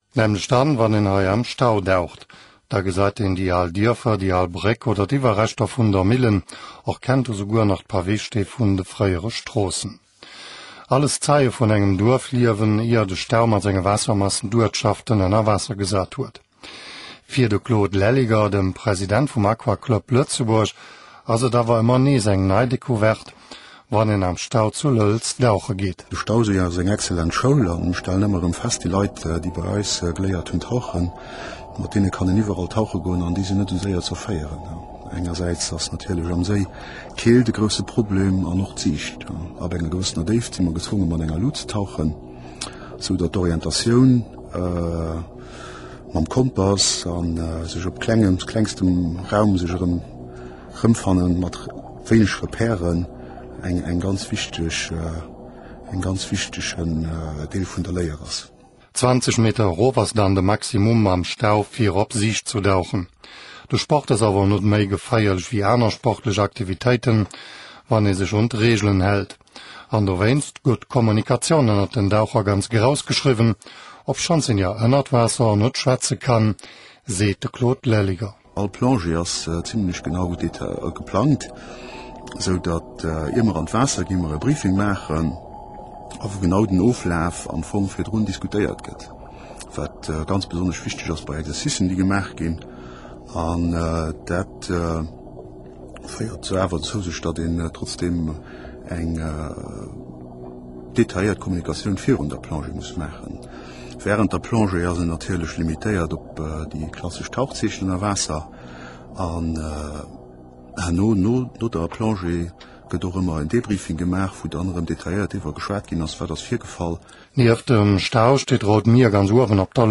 mp38 Interview um 100,7